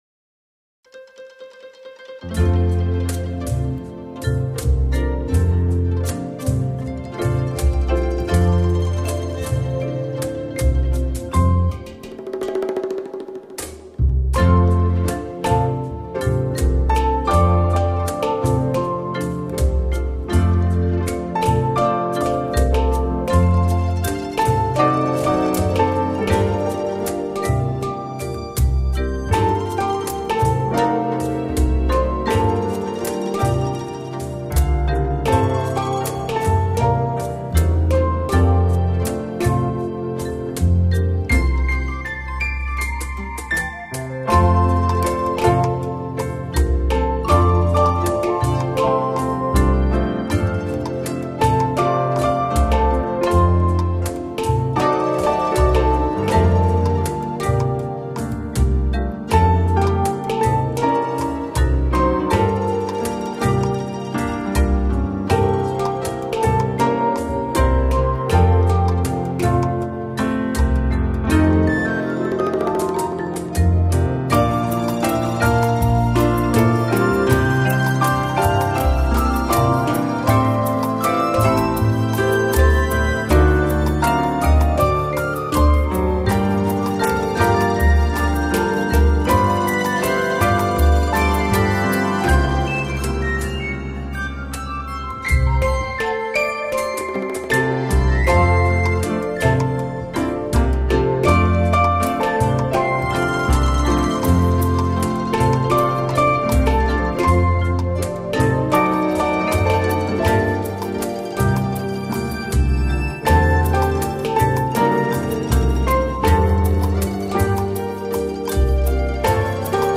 Tiki Play - Another 1950s/60’s exotica style music
The format of this song is very close to the first one, even the bridge is similar, but the feeling of this song is different, more of a children’s melody.
Other percussion is from beathawk.
The bass is from sampletank.
Piano is from pianoteq. Marimba is from a free soundfont.